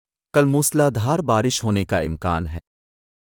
65) "Scenario: In the weather report, the presenter says